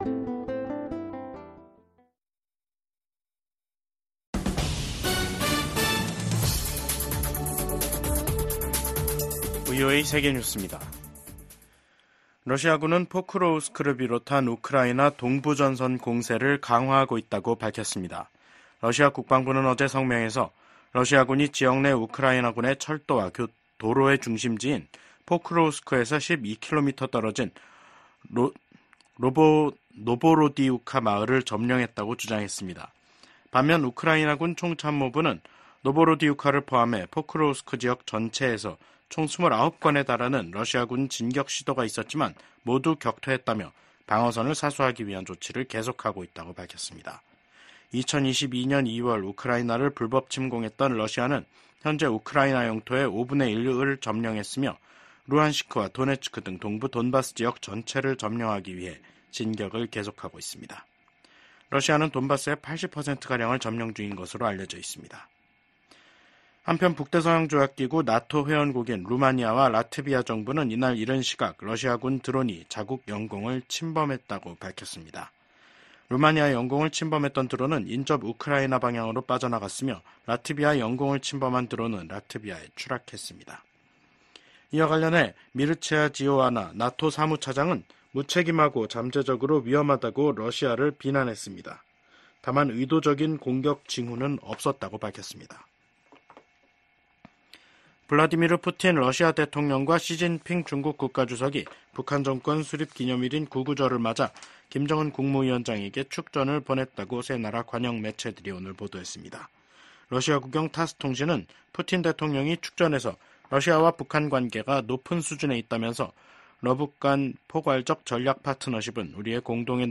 VOA 한국어 간판 뉴스 프로그램 '뉴스 투데이', 2024년 9월 9일 2부 방송입니다. 김정은 북한 국무위원장이 핵과 재래식 전력을 아우르는 군사시설을 한번에 둘러보는 군사 행보에 나섰습니다. 미국 정부는 북한이 7차 핵실험 준비를 마쳤다는 기존 입장을 재확인했습니다.